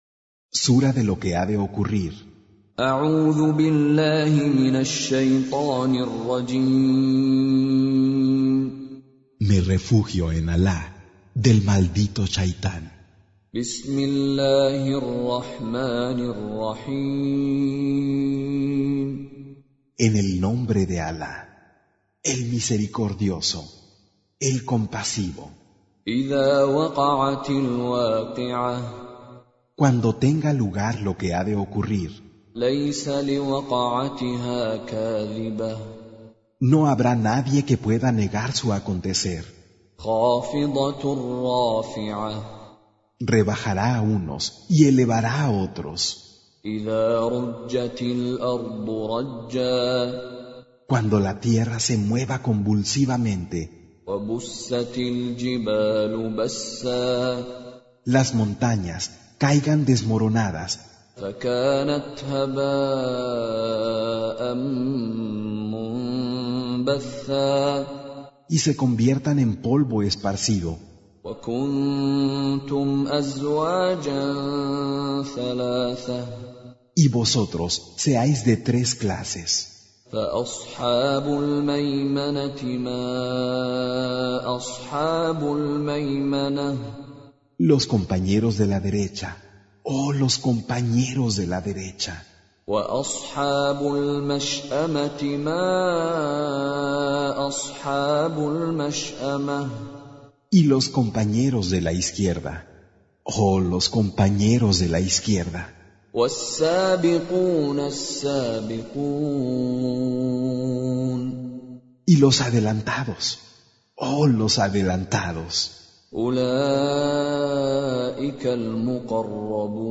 Con Reciter Mishary Alafasi